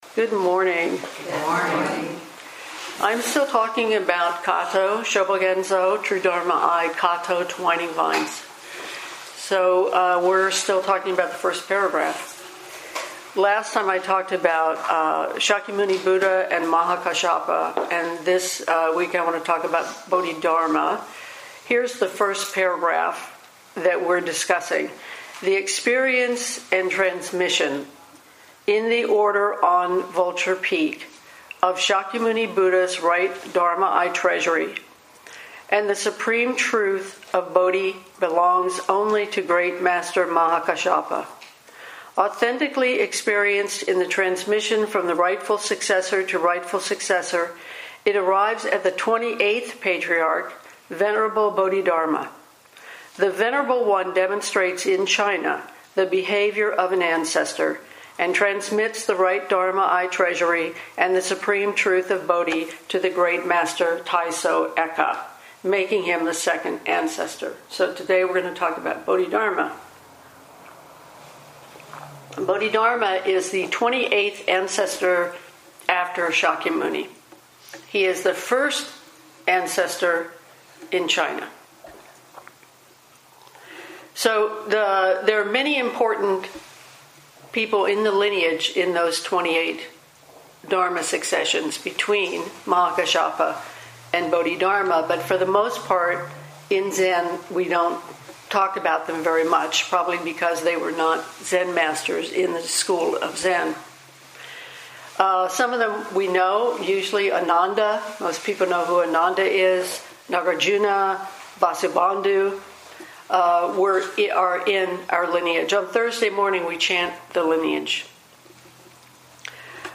2019 in Dharma Talks